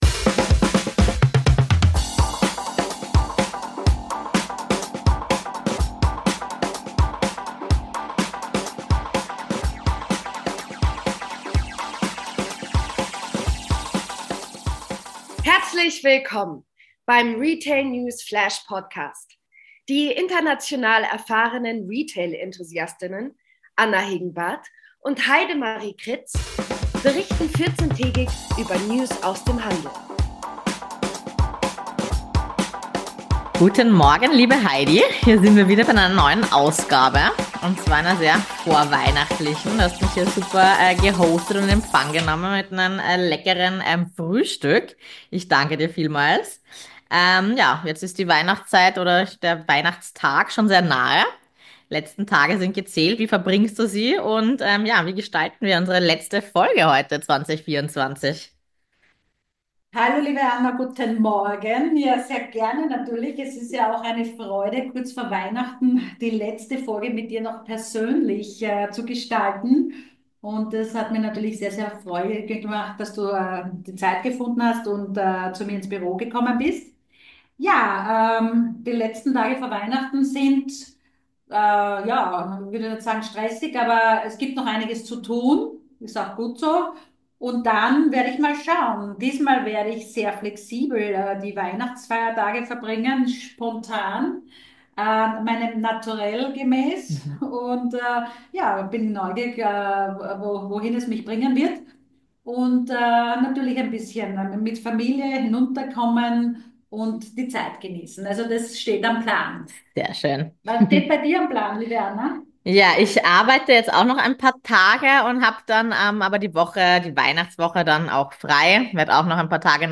Wir sind Retail Enthusiastinnen in unterschiedlichen Bereichen und kommentieren die News fachgerecht.